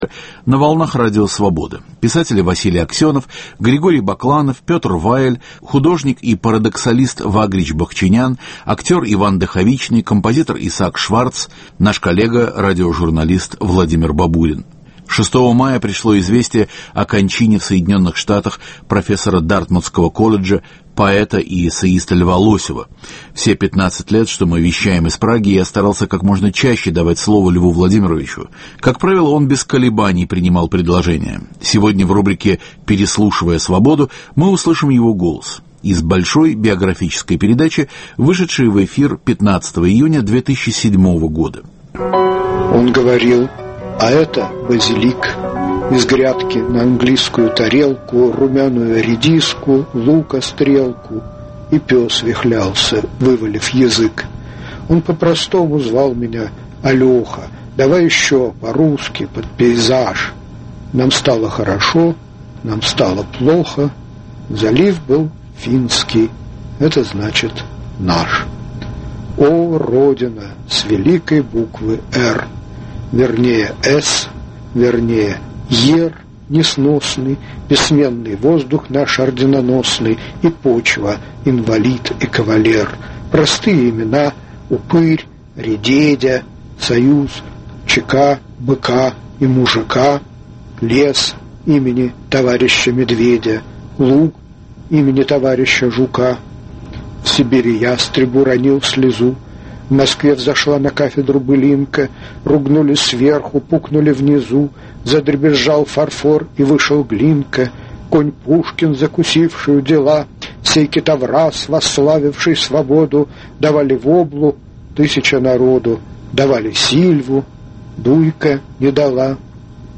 Переслушивая Свободу: Лев Лосев читает свои стихи